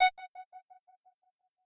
synth1_26.ogg